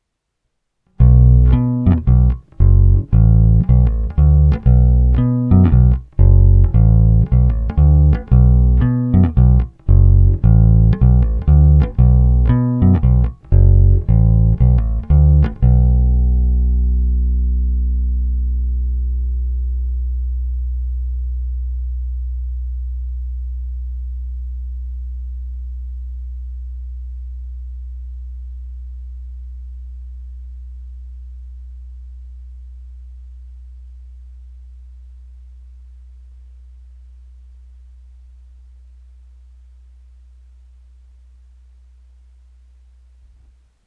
Ne tak zvonivý, ale zato parádně pevný, tučný, a to i s přihlédnutím k tomu, že jsem basu dostal s hlazenkami s nízkým tahem.
Povinné jsou s plně otevřenou tónovou clonou rovnou do zvukovky a jen normalizovány, bonusové jsou se simulacemi aparátu.
Hra u krku